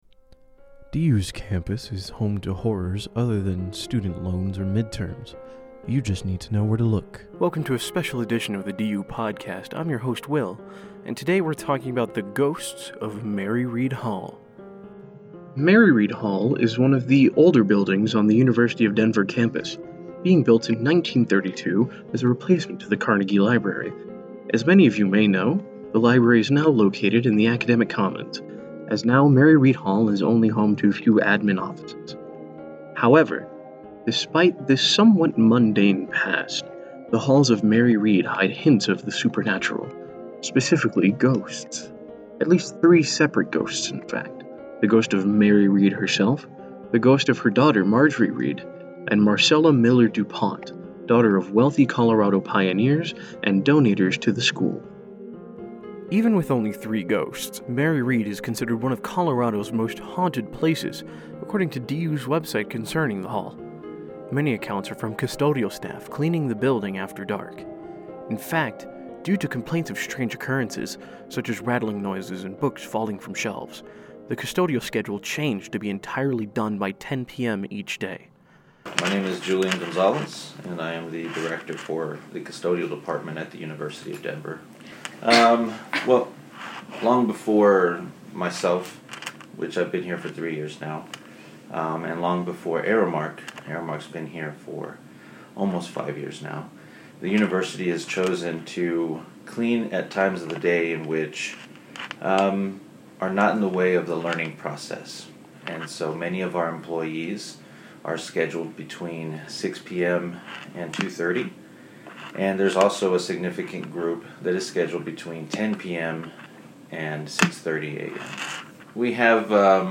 80208 Background music